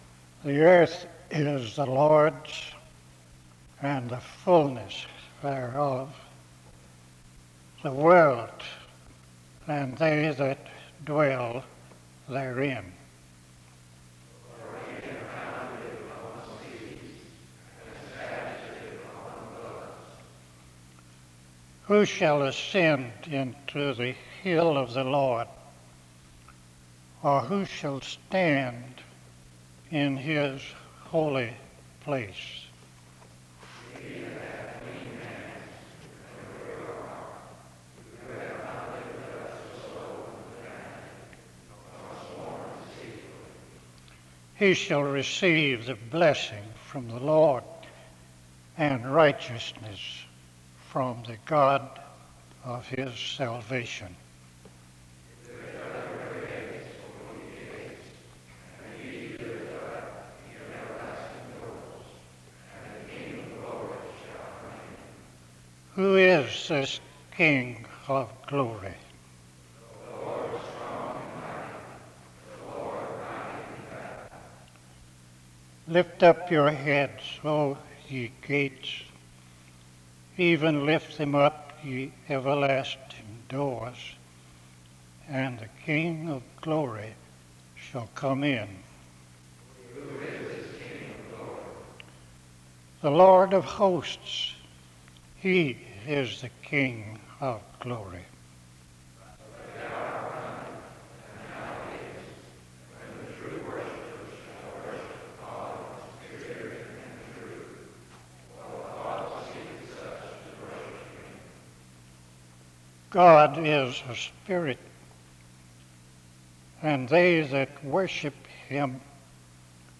The service begins with a responsive reading from 0:00-2:09. A prayer is offered from 2:11-6:16. An introduction to the speaker is given from 6:27-7:34.